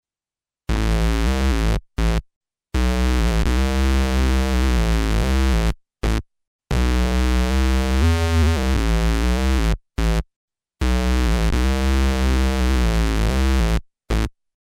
I rimanenti tre campioni stanno a testimoniare proprio questo. con Pad morbidi e sognanti (XioSynth04.mp3), evocative percussioni sintetiche (XioSynth05.mp3) e classici synth bass  (XioSynth06.mp3) lo XioSynth riesce ad esprimersi benissimo in molte tipologie di sonorità sintetiche, con la morbidezza che ormai è marchio di fabbrica in casa Novation.